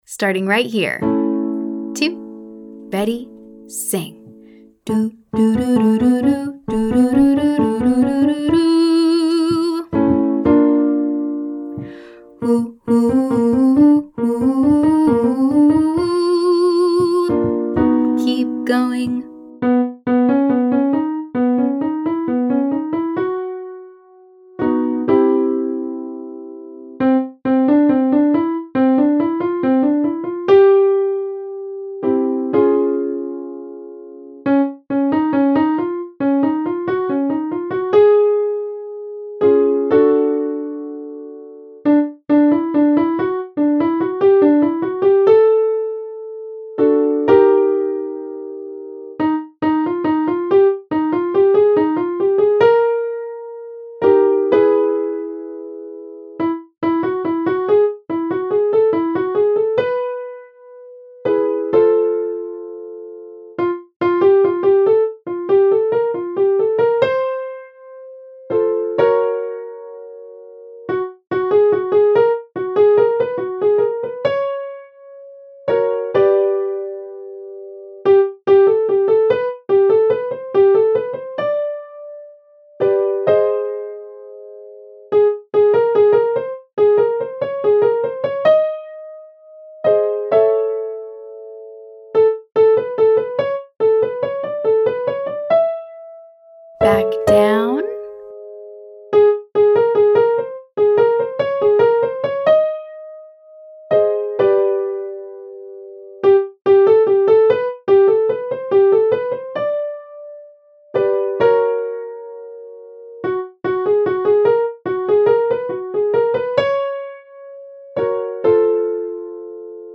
Exercise 2: 1, 12, 123; 1234, 12345
Walking up a 5-tone scale one step at a time, we’ll sing.
Vocal Agility Lesson 3B